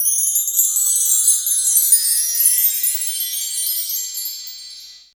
20 WINDCHIME.wav